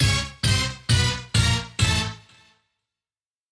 Звуковой эффект разгона